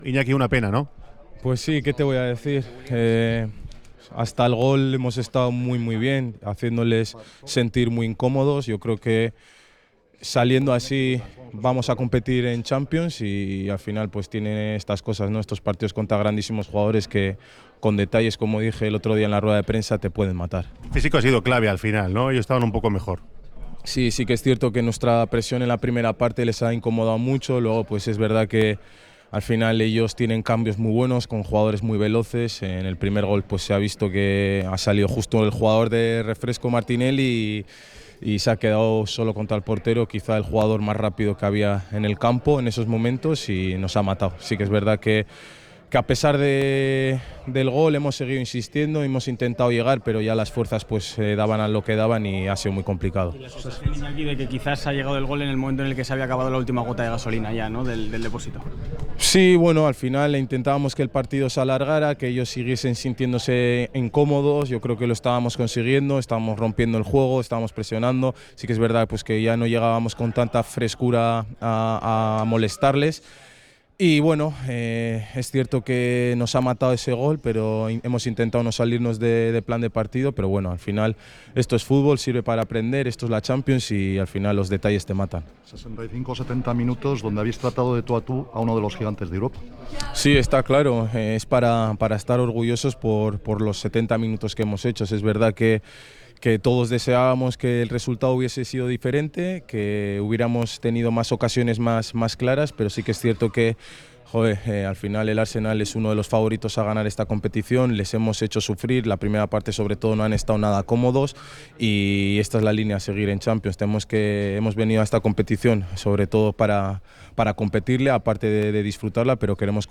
El delantero del Athletic Club, Iñaki Williams, ha compartido sus sensaciones tras el estreno europeo del equipo zurigorri en La Emoción del Bacalao de Radio Popular – Herri Irratia. El atacante rojiblanco ha repasado el desarrollo del encuentro, el esfuerzo colectivo y la emoción de disputar la máxima competición continental.
INAKI-ZONA-MIXTA.mp3